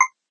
single_tock.ogg